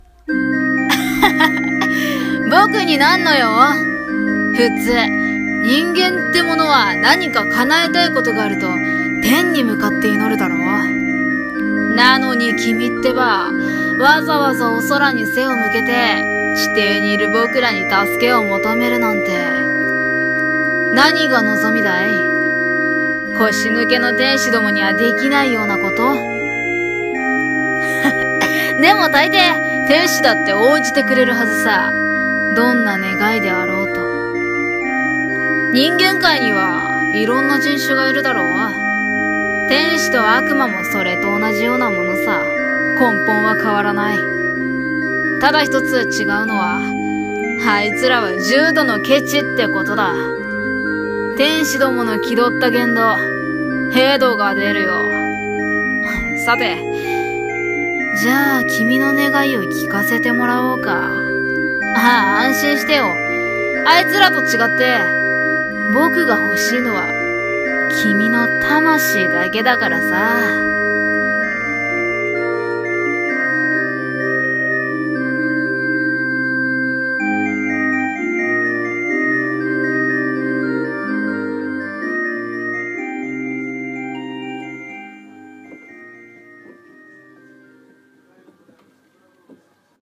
【1人声劇】悪魔と天使